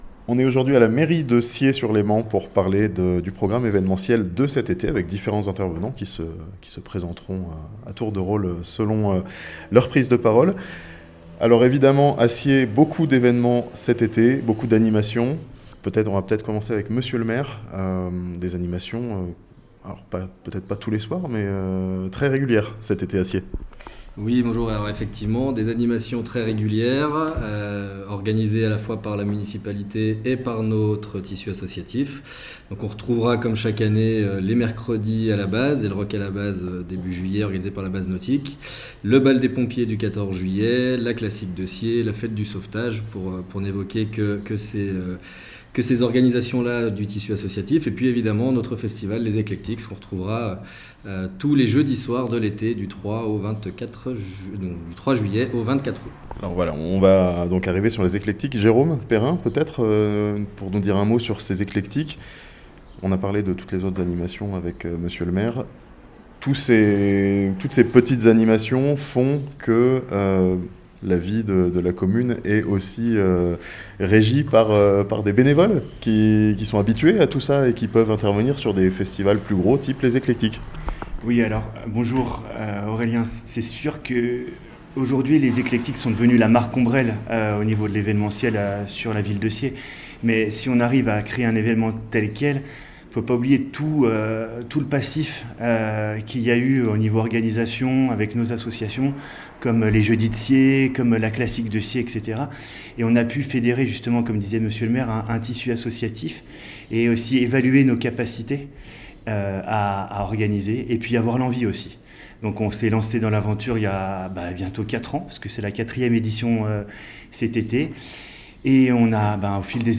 A Sciez, coup d'envoi du festival des Eclectiks ce jeudi 3 juillet (interview)